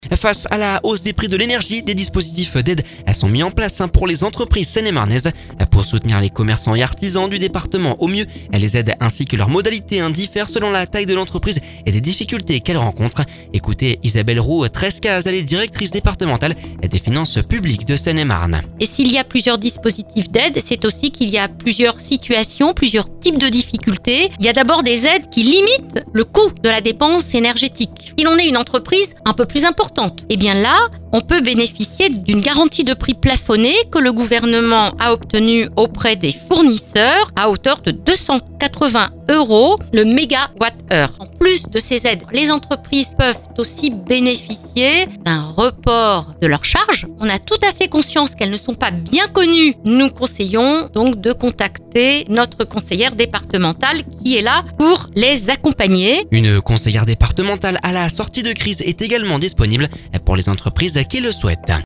Ecoutez Isabelle-Roux Trescases, directrice départementale des finances publiques de Seine et Marne…